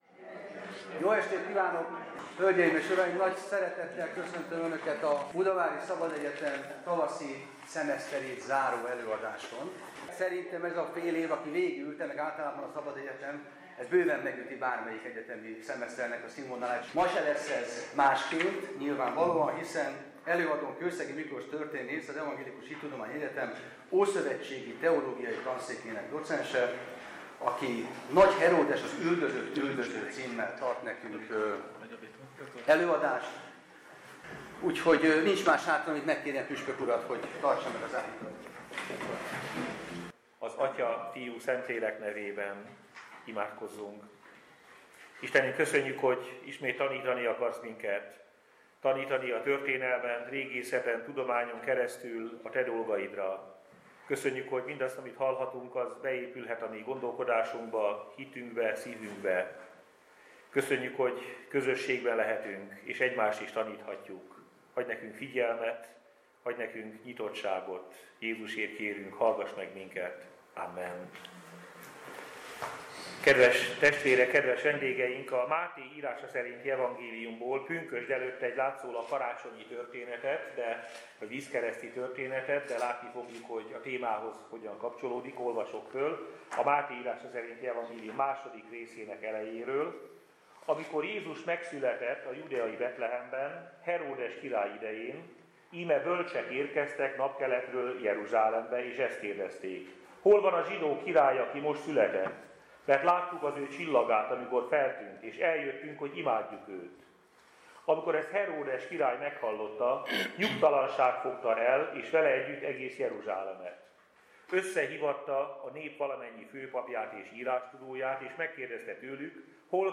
Budapest – Parádés előadással zárta idei tavaszi szemeszterét a budavári evangélikus szabadegyetem.
Pünkösd felé közeledve egy karácsonyi történettel indította az alkalmat Fabiny Tamás elnök-püspök, amikor a Máté írása szerinti evangéliumból felolvasta a napkeleti bölcsek történetét.